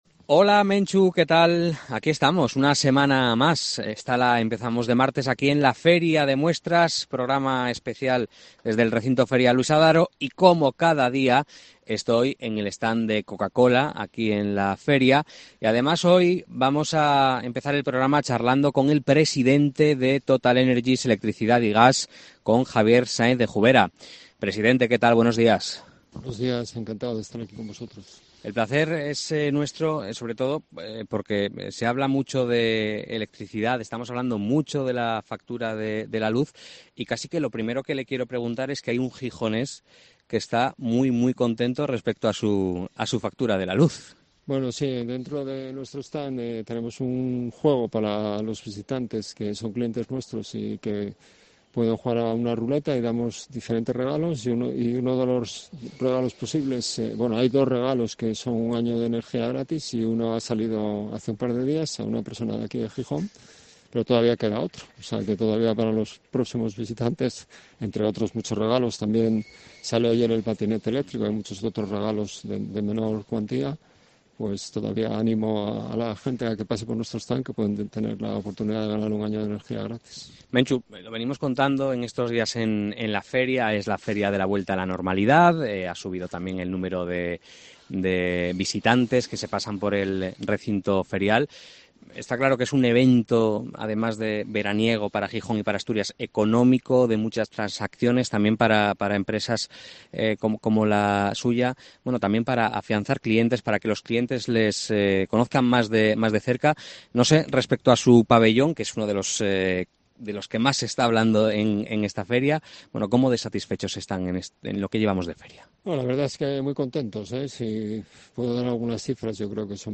FIDMA 2022: Entrevista